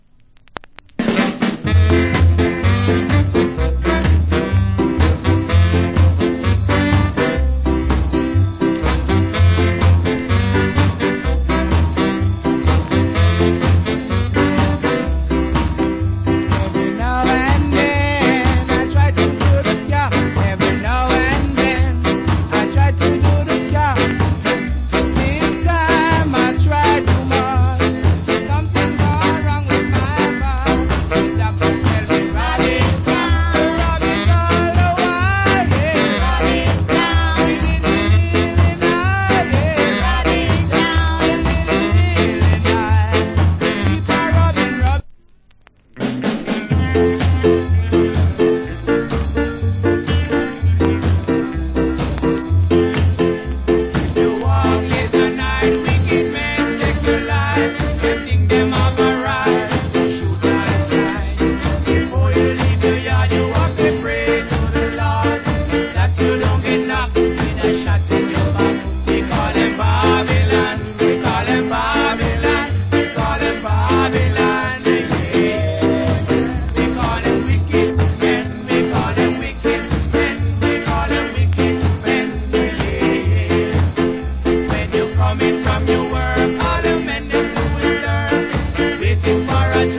Wicked Ska Vocal.